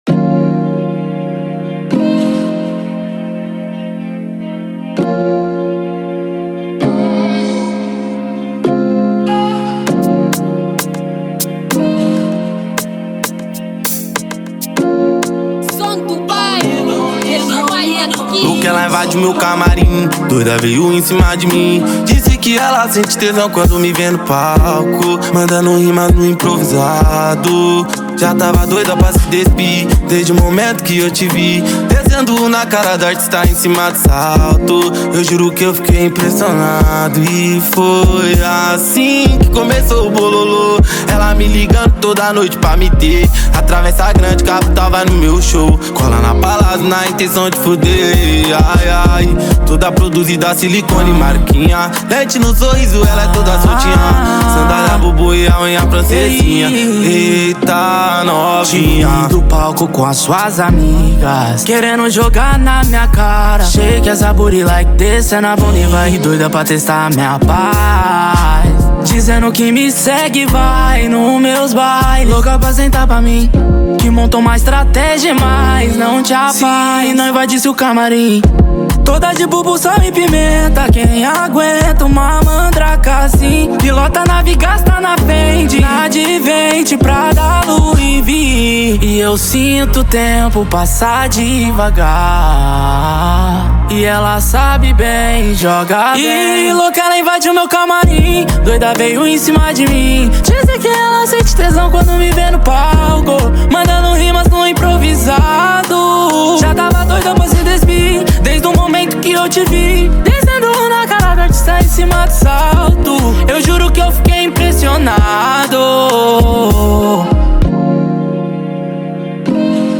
Genero: RAP